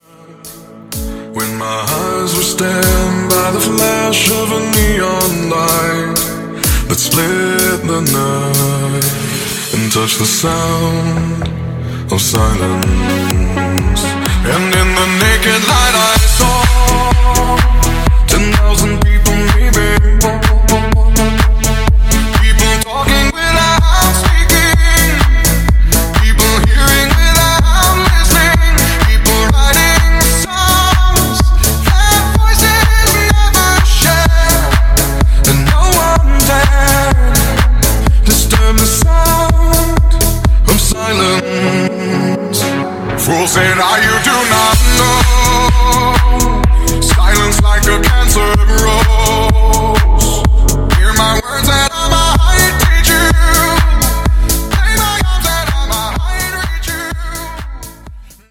Genre: DANCE
Clean BPM: 130 Time